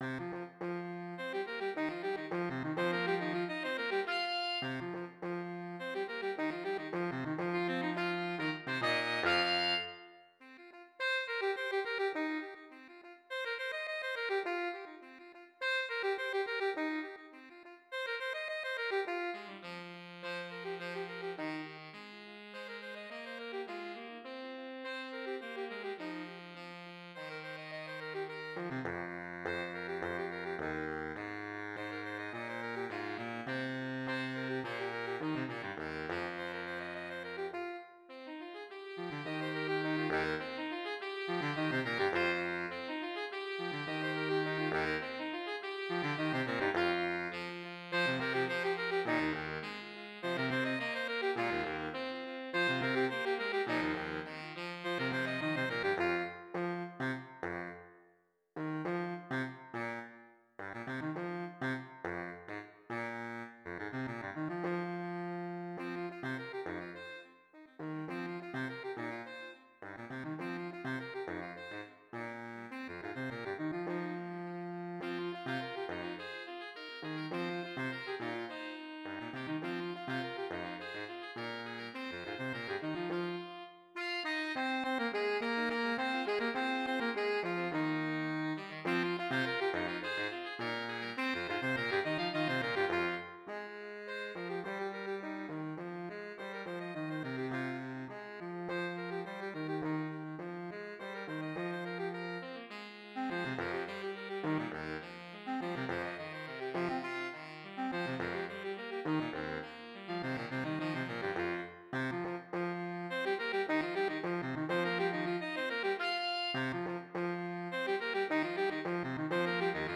Voicing: Saxophone Quartet